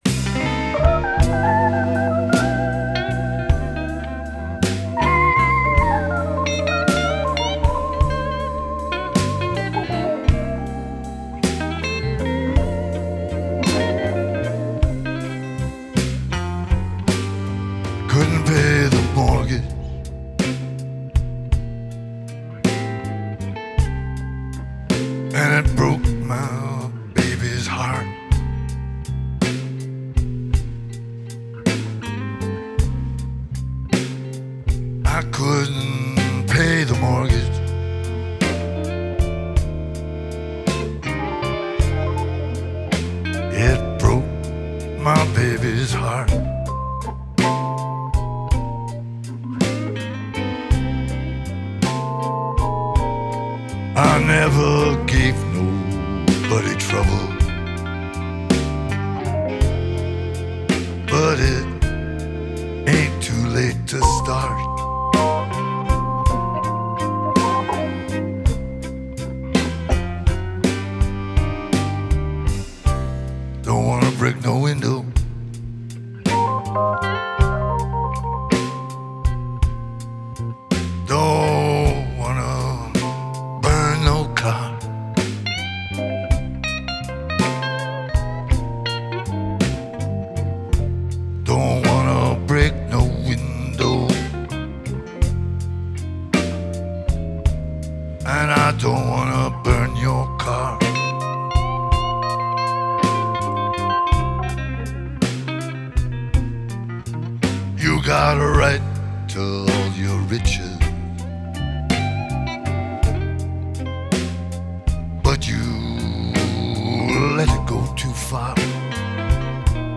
Genre: Folk, Blues, Singer-Songwriter
Live at Odense Soundcheck, 2013